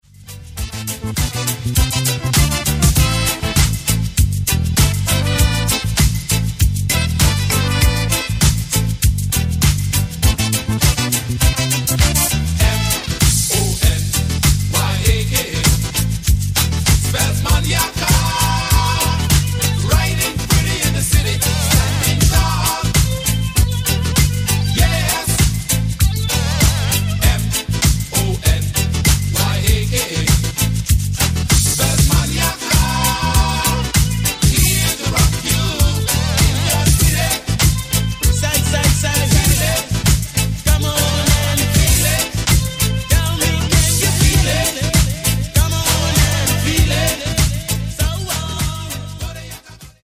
Genere:   Disco | Electro Funky